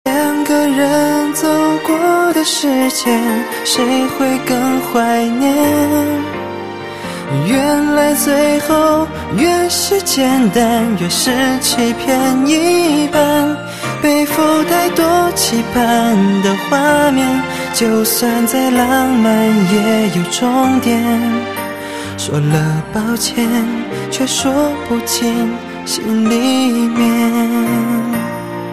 M4R铃声, MP3铃声, 华语歌曲 114 首发日期：2018-05-15 19:49 星期二